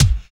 103 KICK.wav